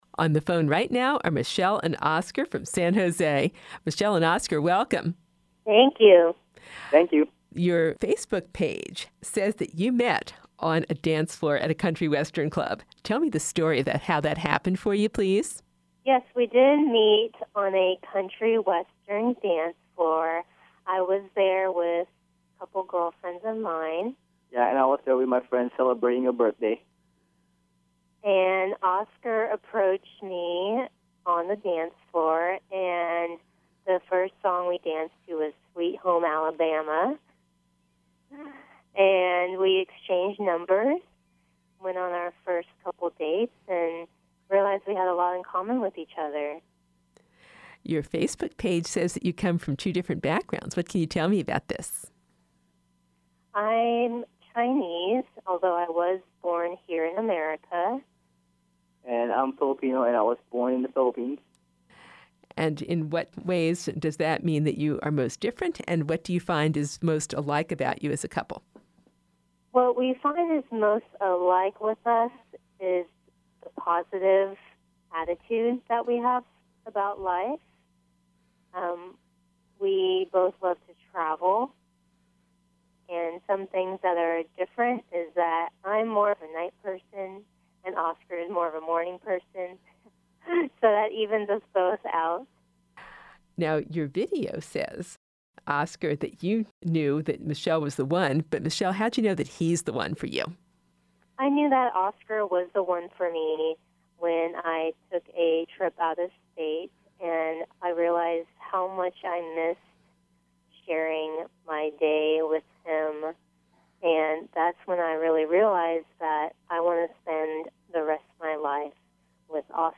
Interview, Part 1